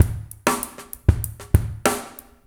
PRP DR1WET-L.wav